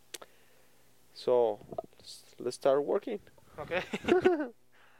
Functions of Bookended Narrow-Pitch-Range Regions
5. Proposing, Spanish style